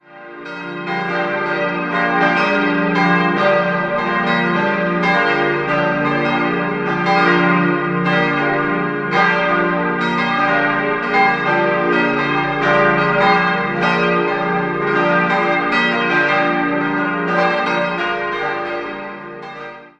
Das heutige Gotteshaus konnte 1721 fertiggestellt werden. Idealquartett d'-f'-g'-b' Die Glocken wurden 1957 von Rincker in Sinn gegossen.